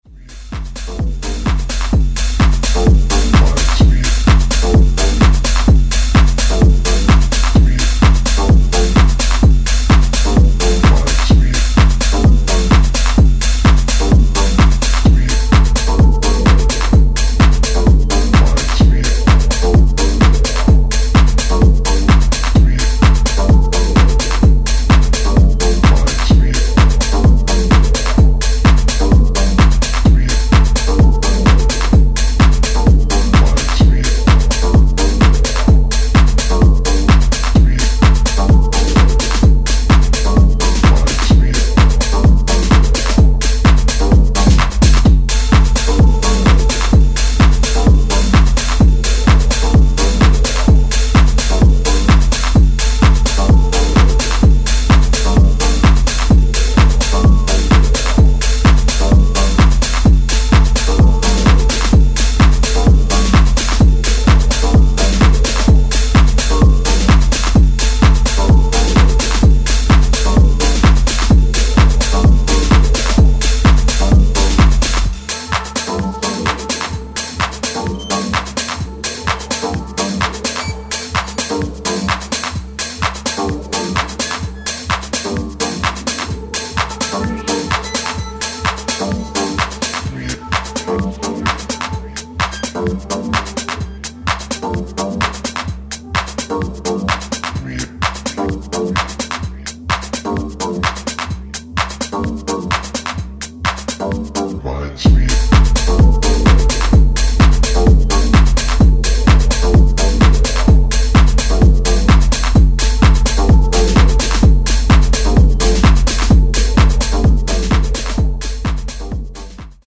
raw and heavy